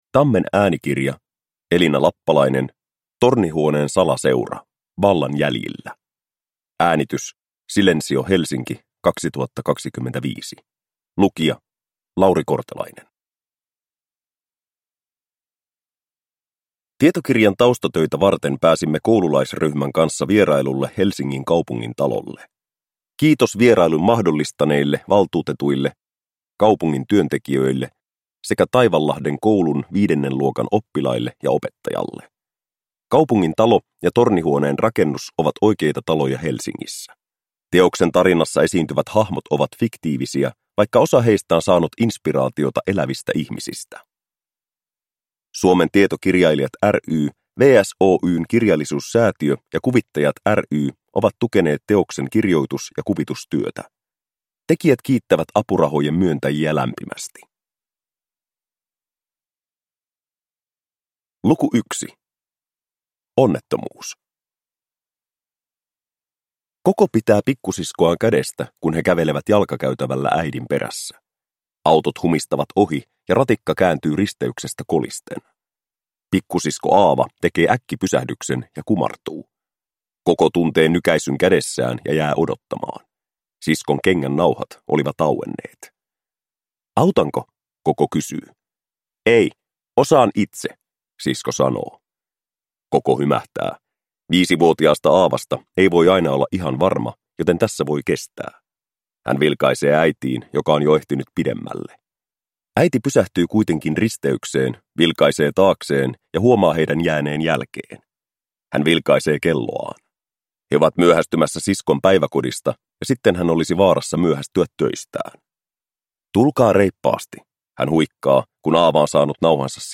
Tornihuoneen salaseura. Vallan jäljillä (ljudbok) av Elina Lappalainen